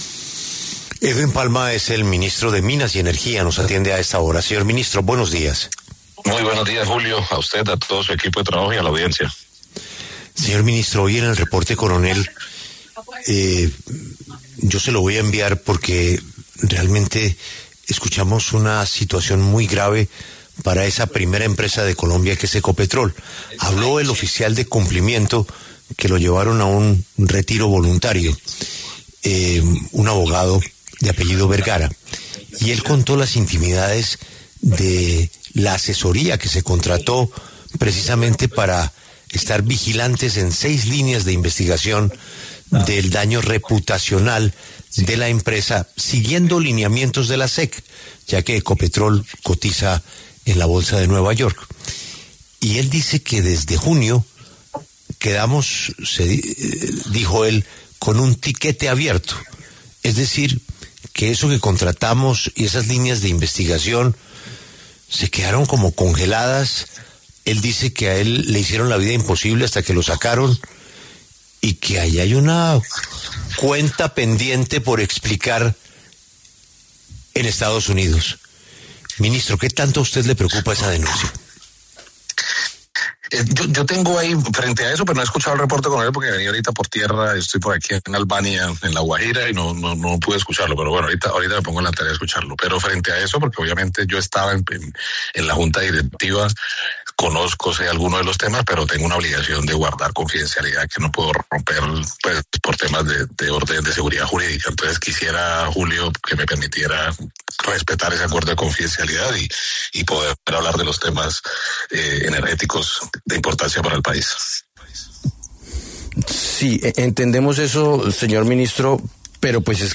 En diálogo con La W, el ministro de Minas y Energía aseguró que se sigue estudiando la posibilidad de adquirir Monómeros a través de Indumil en asocio con un privado.